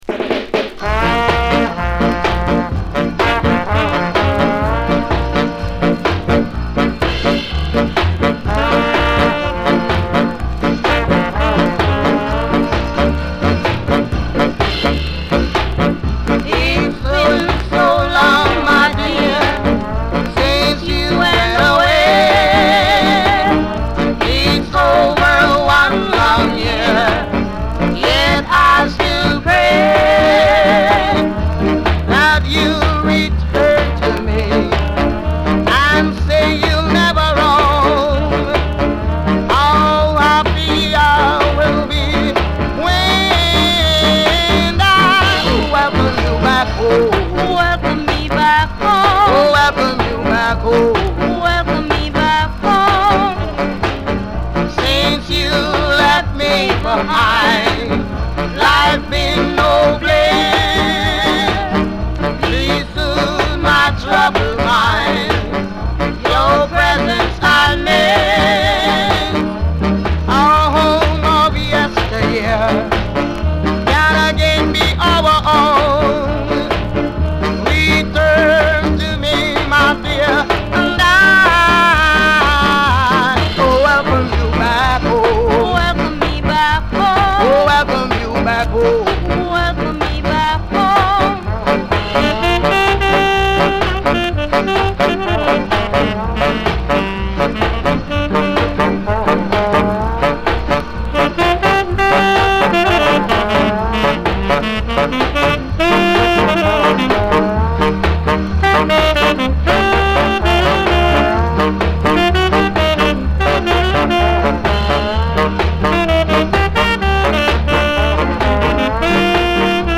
duets2 #3